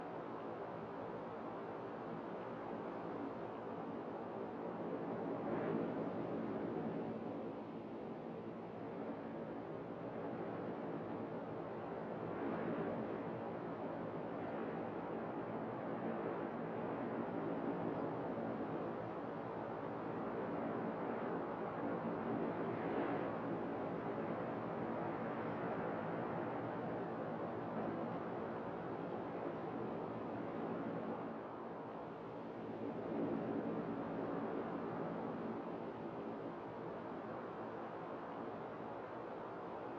Wind Loop 3.wav